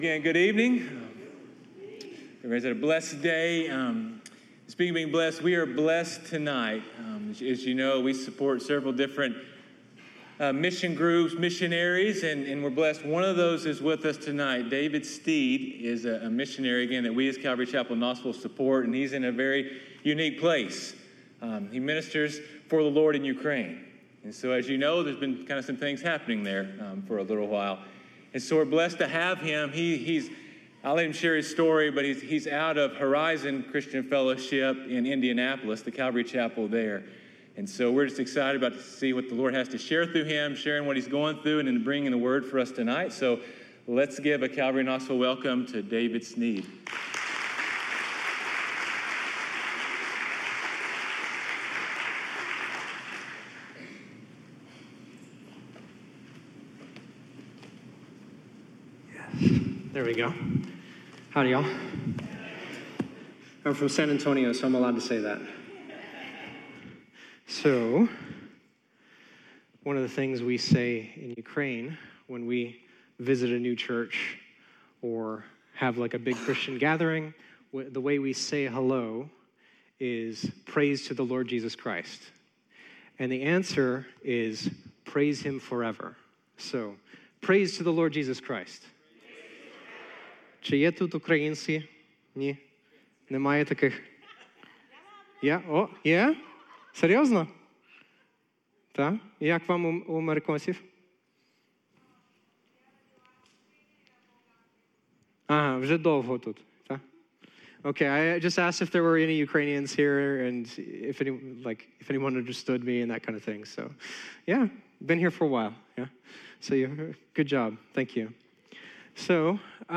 sermons
Calvary Chapel Knoxville